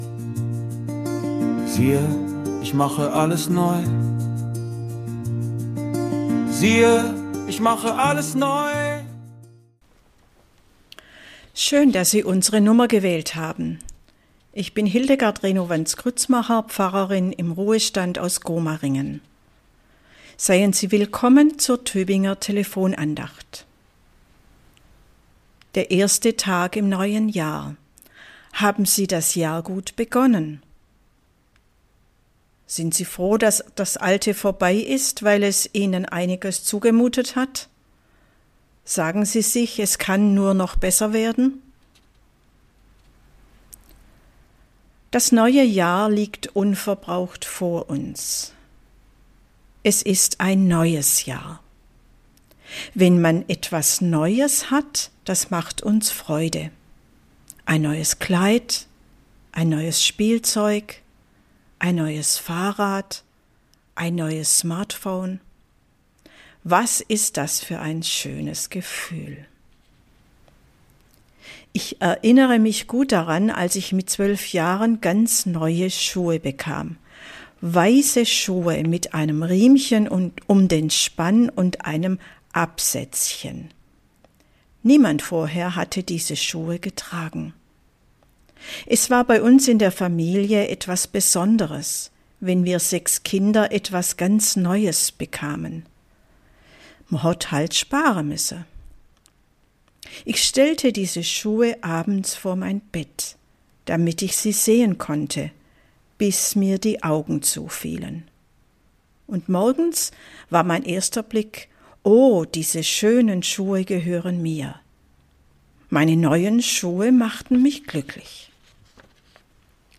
Andacht zur Jahreslosung